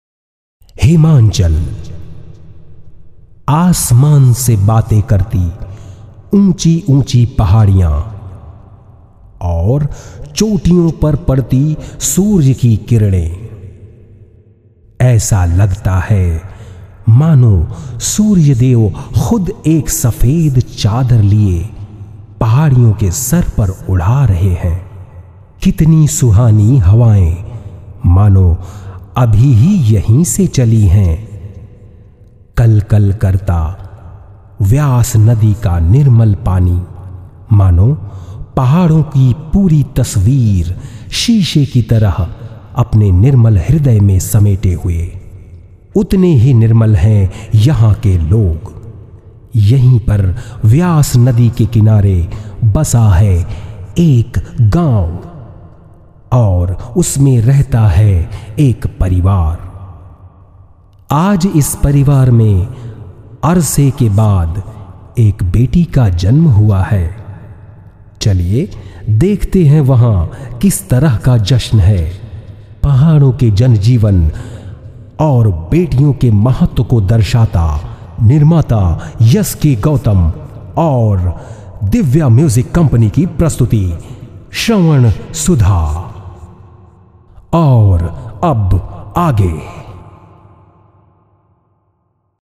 His sweet and warm, rich and distinctively English and Hindi Voice has enhanced the image of many of today’s leading brands .
Sprechprobe: Werbung (Muttersprache):